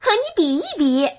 wnmj-normal/Resources/Games/ZJH/Sound/woman/bipai.mp3 at bc77cb13ed412de4c87060e0c01fd62caf9b354e